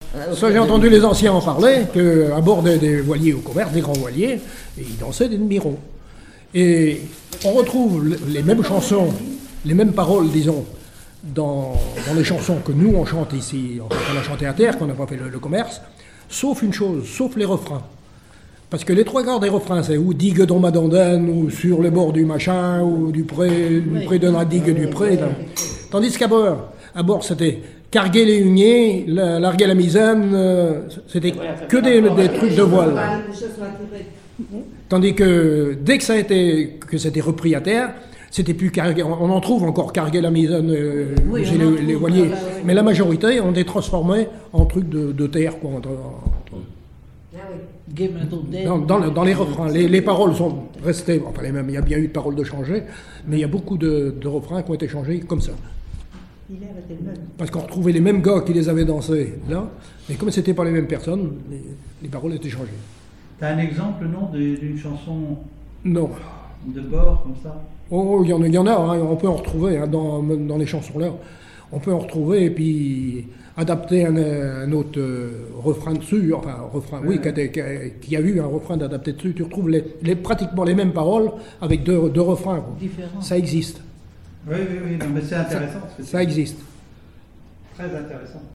chanteur(s), chant, chanson, chansonnette
Île-d'Yeu (L')
répertoire de chansons traditionnelles